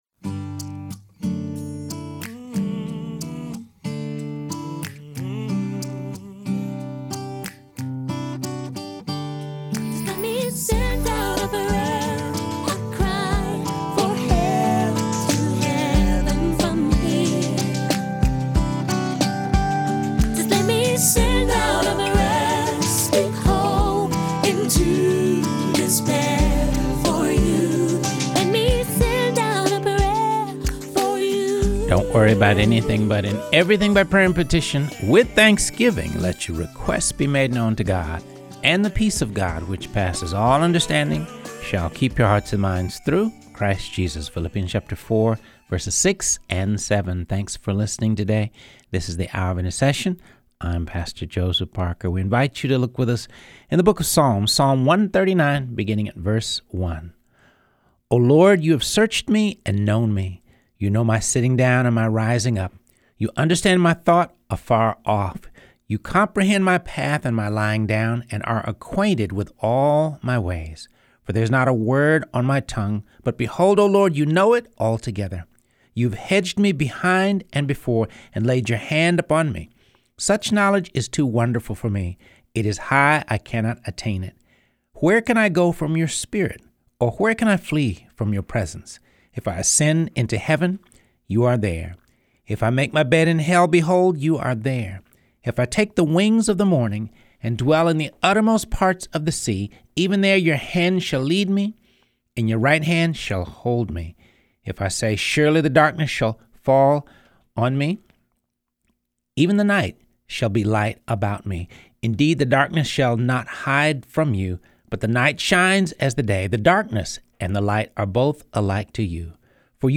Be'ad Chaim, a Ministry Helping Israeli Women and Men Choose Life | Rebroadcast